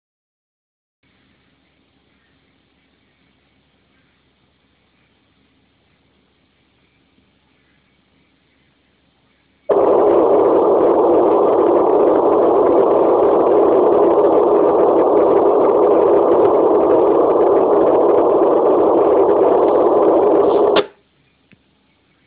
dataljud
Det sänds med jämna mellanrum ut på en av de licensfria kanalerna på 400Mhz.